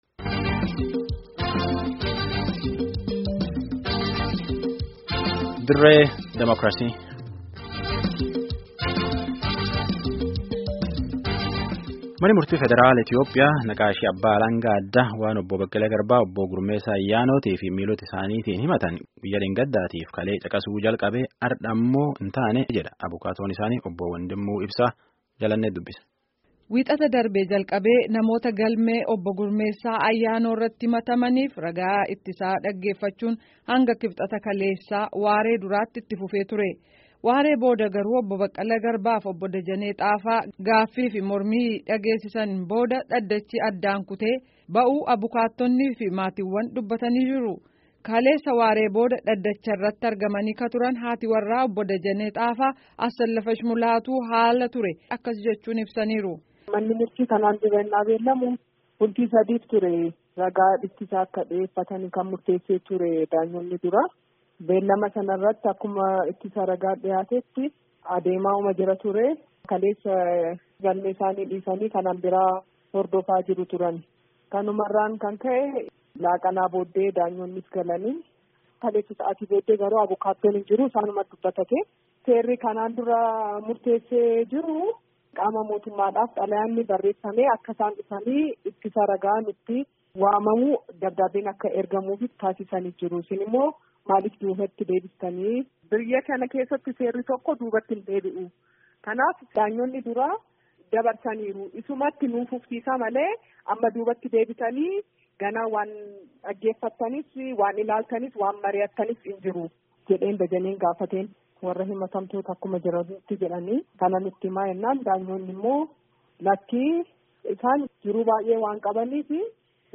Gaaffii fi Deebii maatii fi abokaatoo waliin gaggeeffame caqasaa.